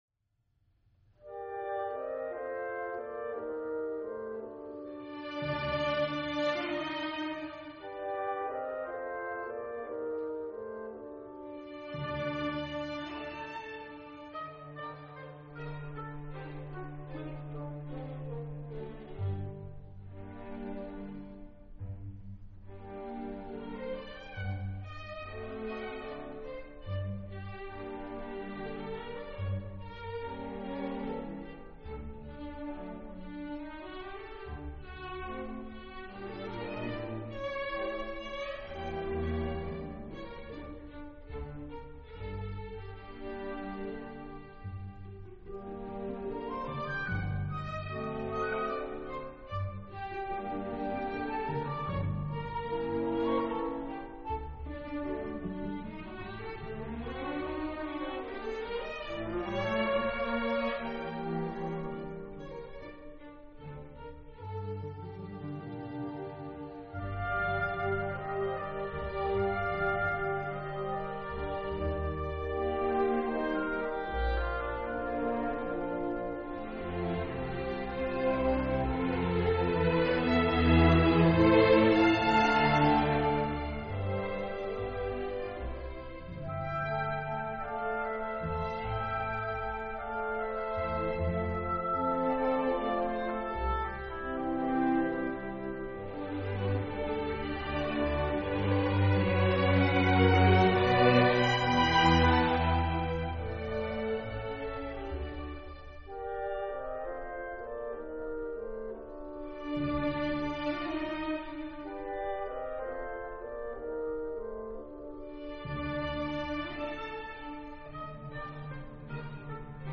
orchestral miniature